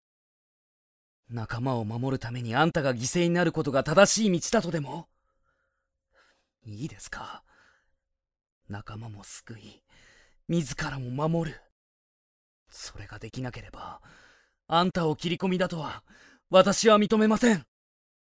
【サンプルセリフ】
（自分が捨て駒になるからと言う夕霧に、珍しく食って掛かる）
雰囲気的には朴訥な感じなのにきりっとした敬語という、少し不思議なタイプになりました。